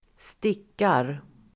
Uttal: [²st'ik:ar]